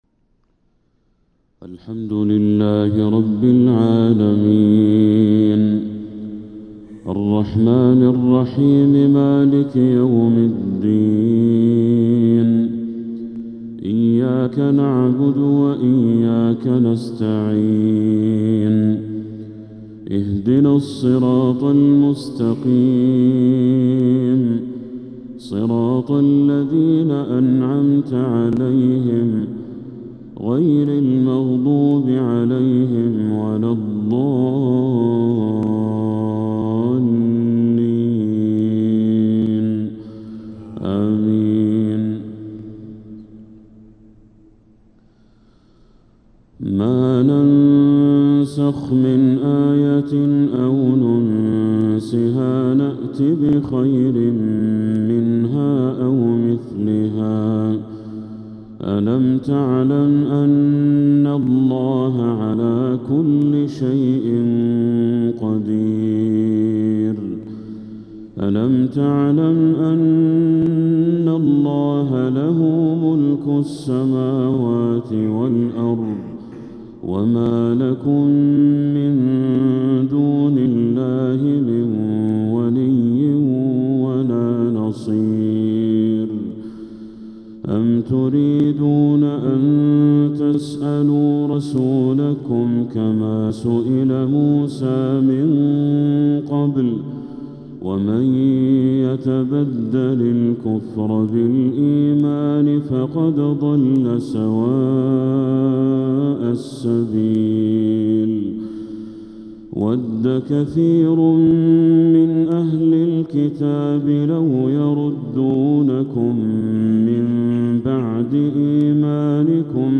فجر الثلاثاء 6 محرم 1447هـ من سورة البقرة 106-119 | Fajr prayer from Surah Al-Baqarah 1-7-2025 > 1447 🕋 > الفروض - تلاوات الحرمين